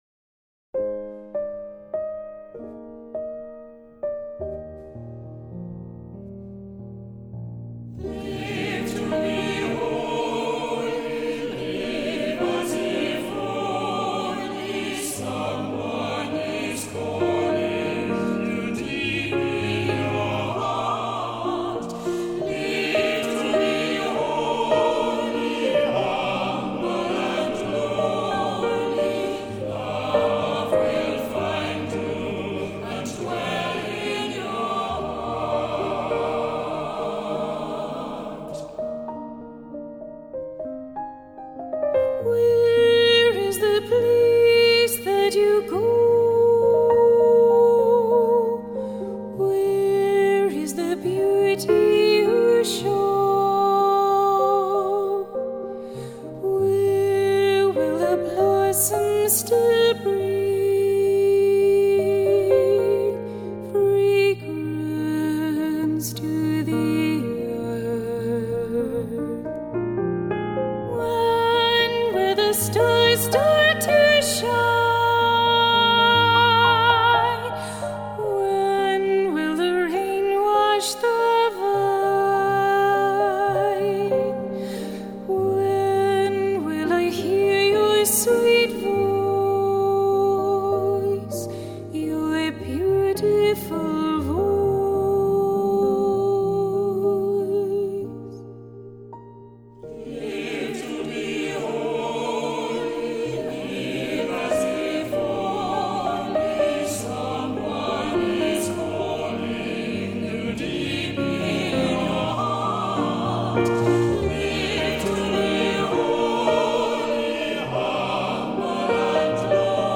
Voicing: "SATB","Cantor","Assembly"